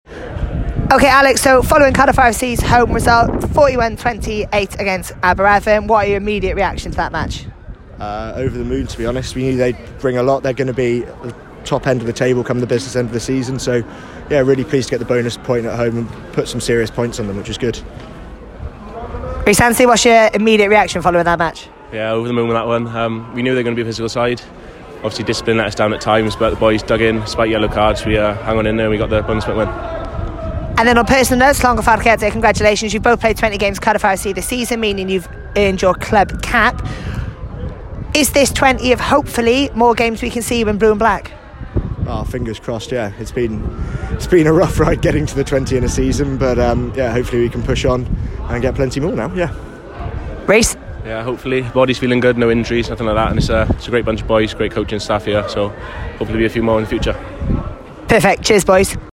Post-Match Interviews